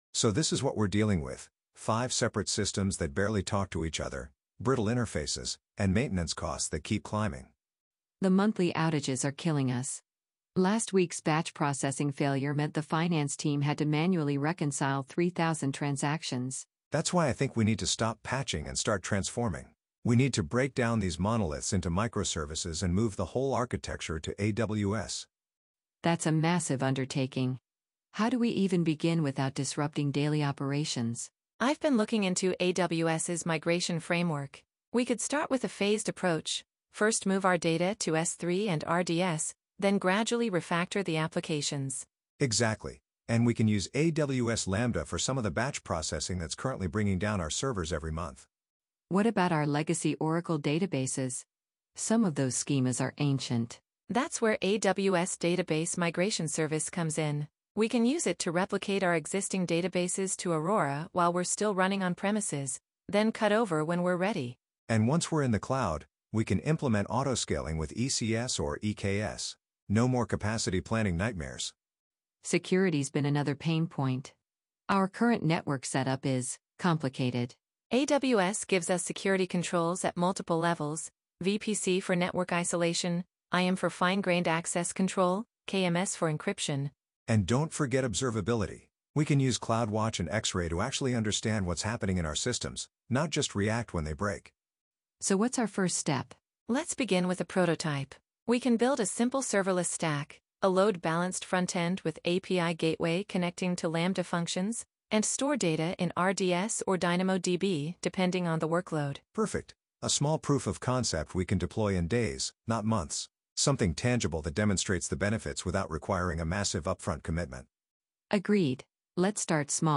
The meeting audio was synthesized using Amazon Polly to bring the conversation to life for this post.
meeting-conversation.mp3